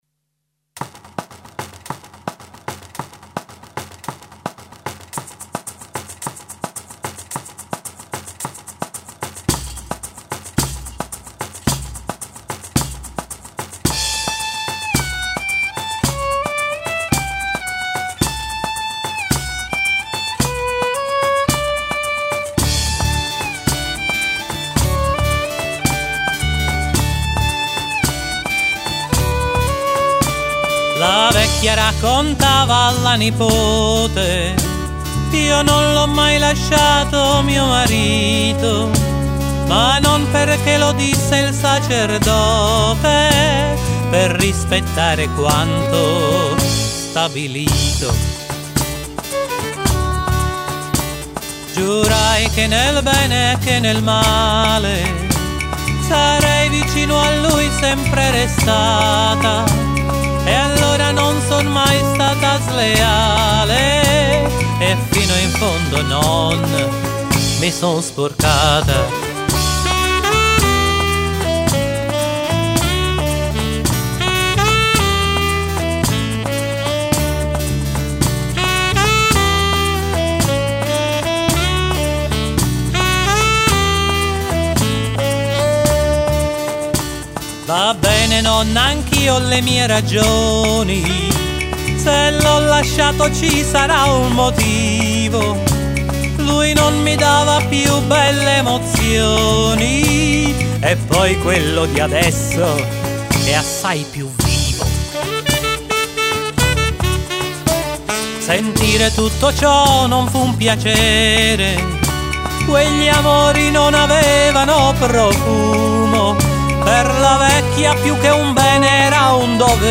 CD autoprodotto
Registrato presso Artestudio53 - Firenze
Chitarra e voce
Pianoforte
Tastiera & Programmazione
Chitarra classica
Sax & Clarinetto
Violino
Fisarmonica